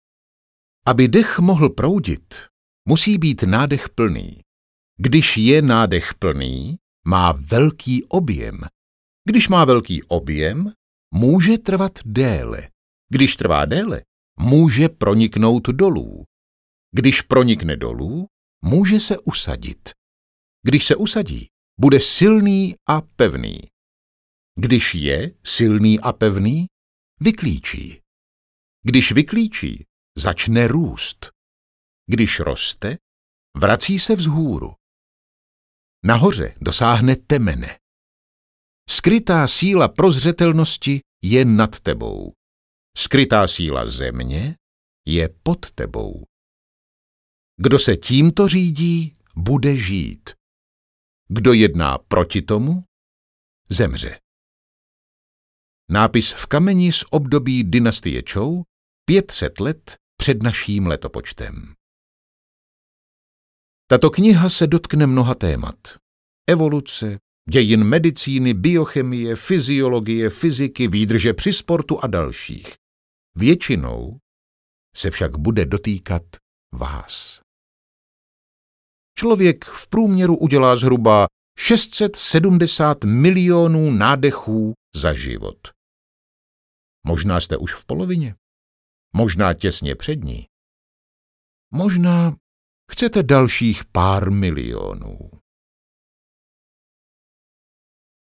Dech audiokniha
Ukázka z knihy